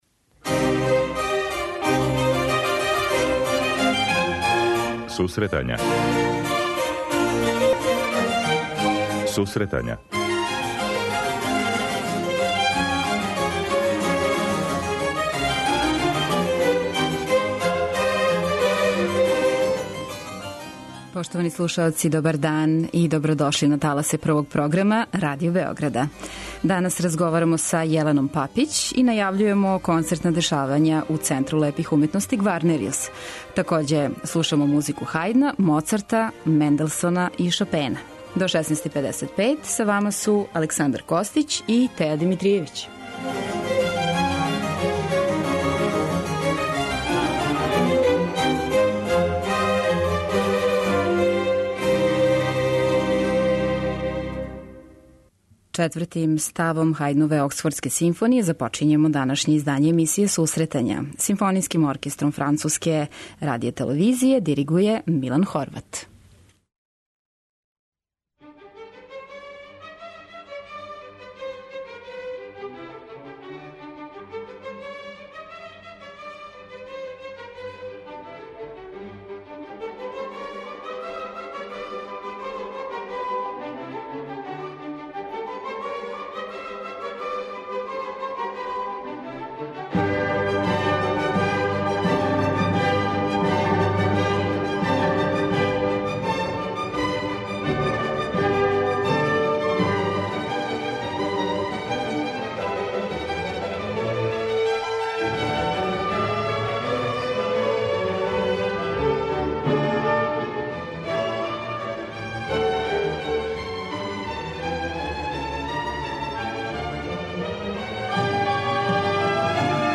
преузми : 23.05 MB Сусретања Autor: Музичка редакција Емисија за оне који воле уметничку музику.